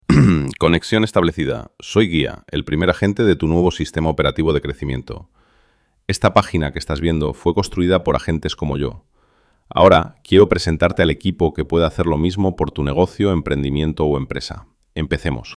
Voz masculina guiahelp pagina.mp3